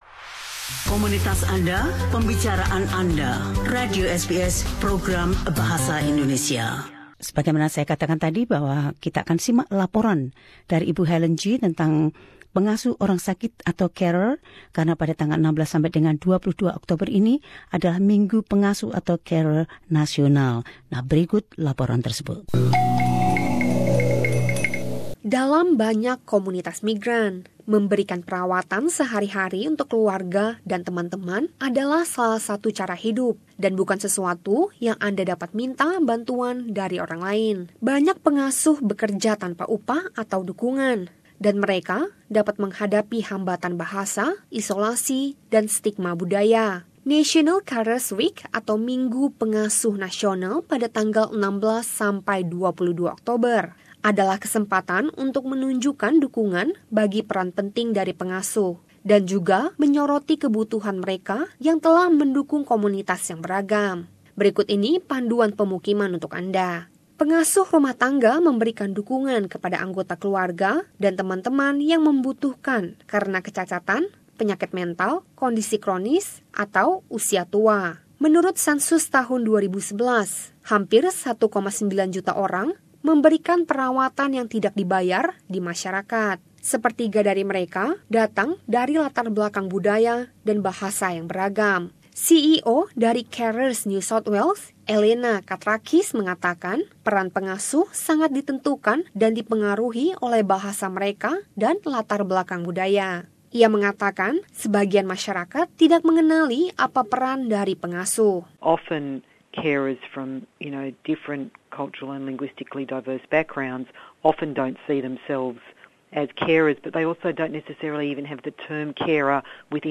Laporan ini membahas masalah yang menyangkut peran dari pengasuh dalam multicultural Australia.